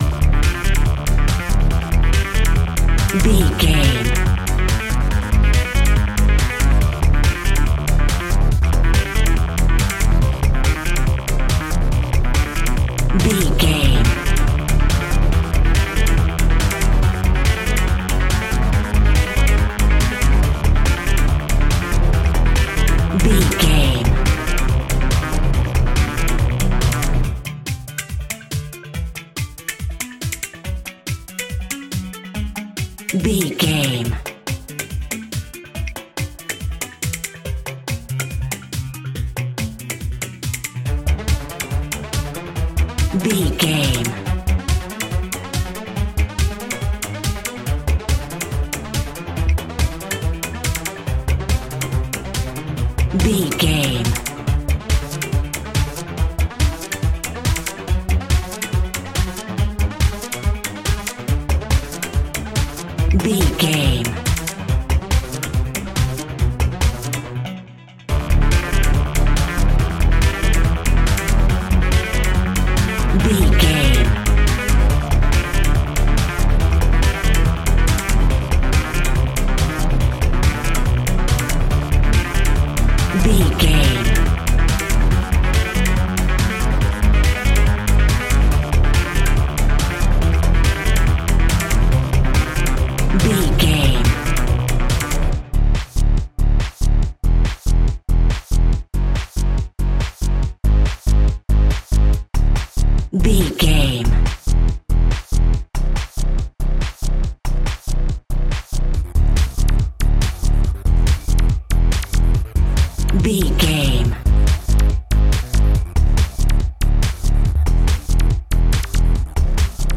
Epic / Action
Fast paced
In-crescendo
Aeolian/Minor
Fast
dark
futuristic
groovy
aggressive
frantic
synthesiser
drum machine
strings
Drum and bass
break beat
sub bass
techno
synth lead
synth bass